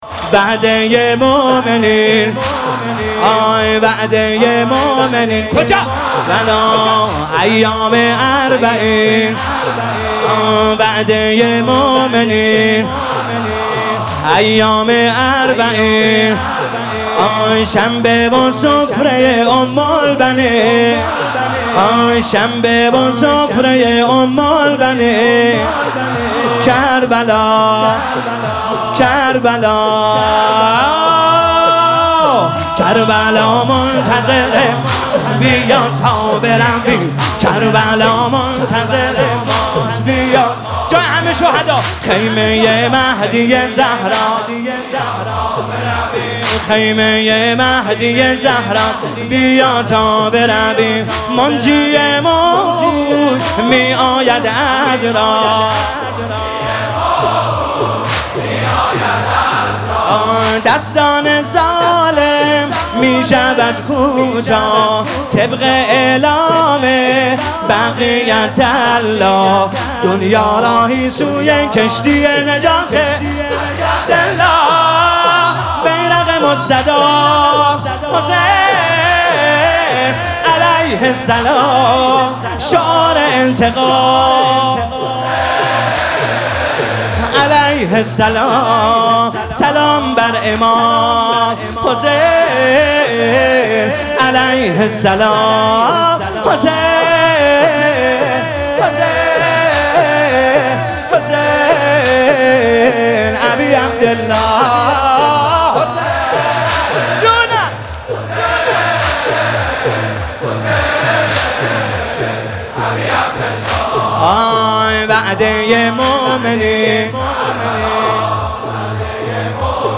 محفل عزاداری شب دوم محرم